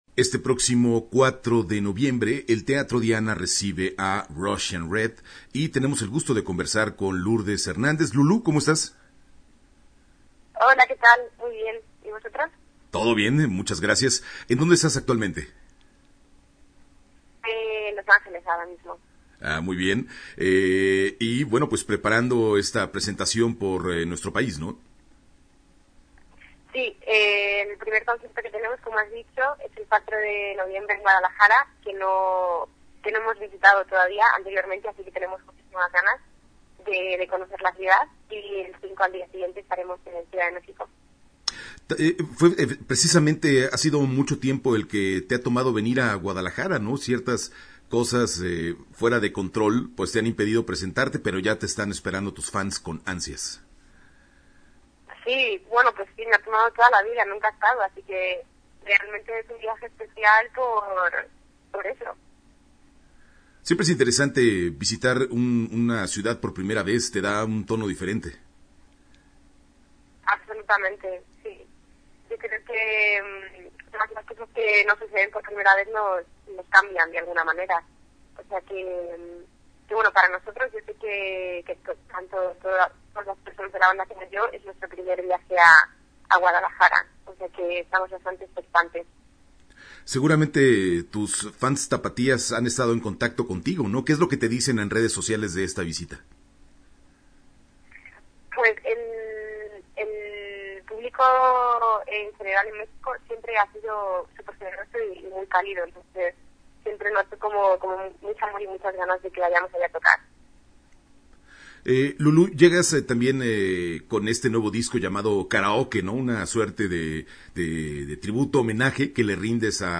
Entrevista-Russian-Red-web.mp3